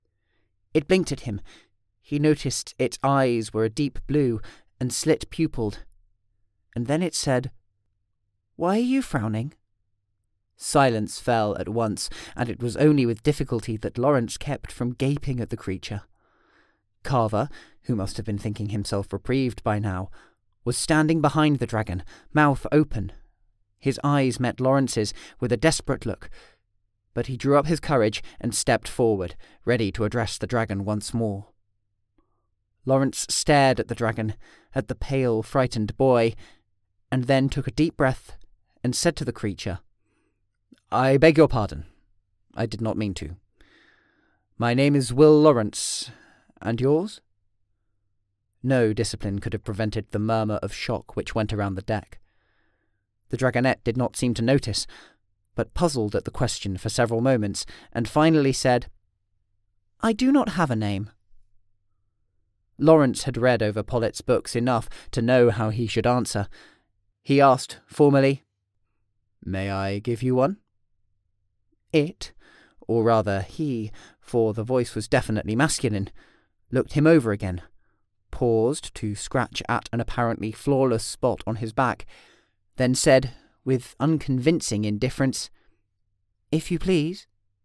Fantasy Audiobook Sample
Male
Neutral British
Youthful
audiobook-sample-fantasy.mp3